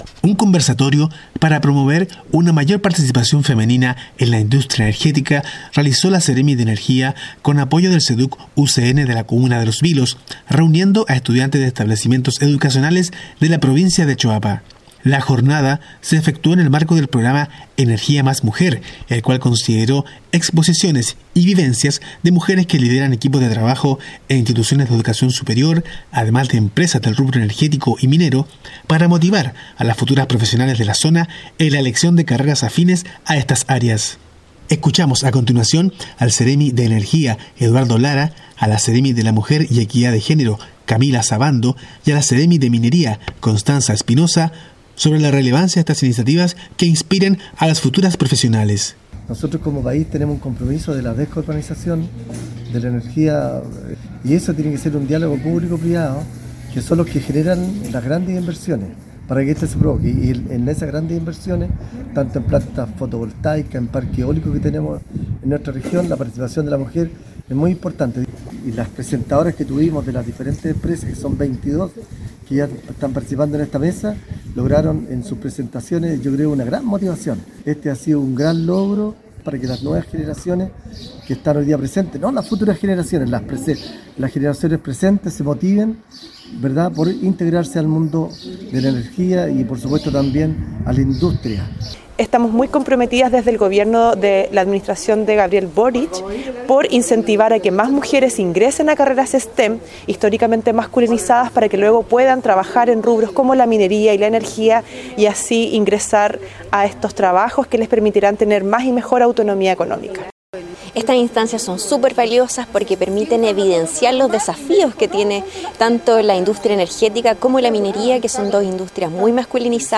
DESPACHO-CONVERSATORIO-ENERGIA-19-08.mp3